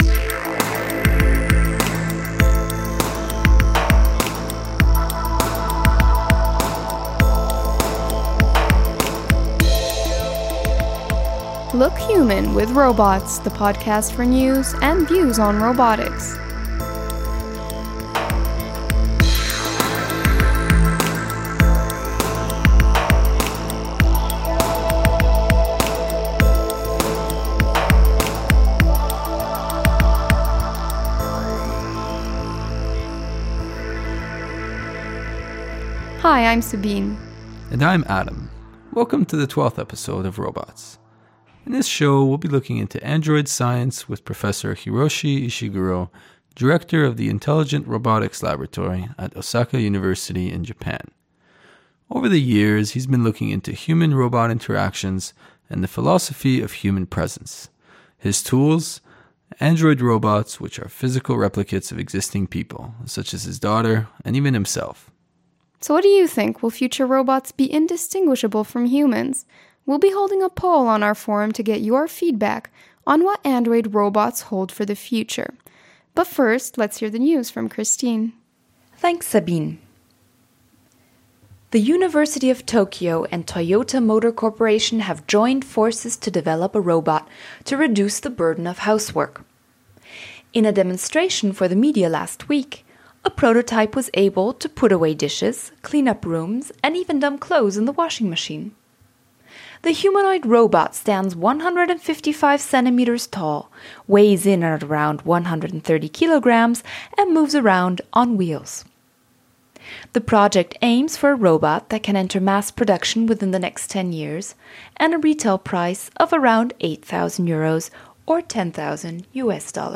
This episode covers android science and human-robot interactions with expert Hiroshi Ishiguro from Osaka.